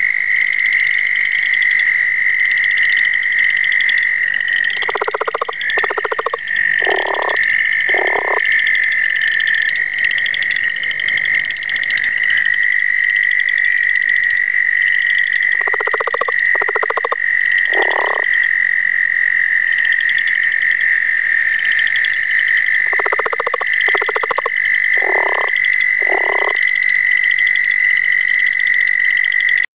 Here's an amazing chorus of Pseudacris nigrita, Limnaoedus ocularis, Rana sphenocephala, and an overwhelming number of Bufo terrestris!